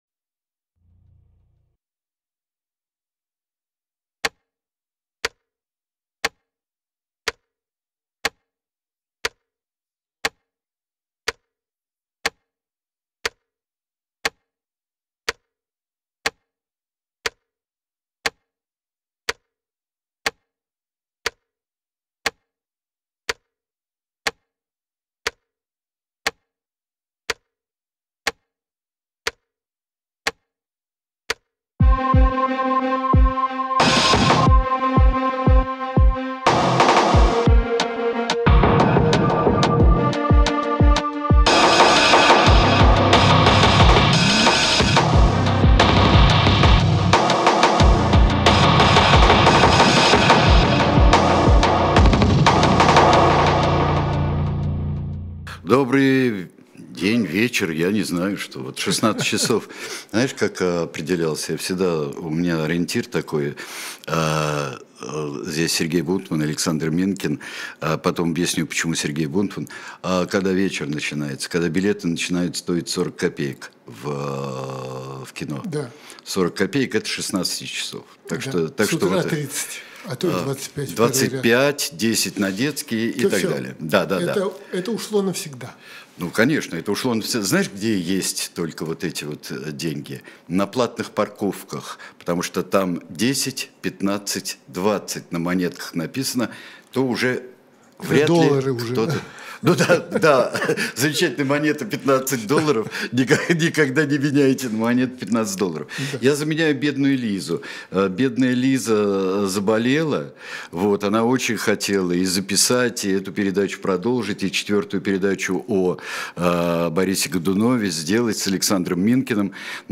Эфир ведёт Сергей Бунтман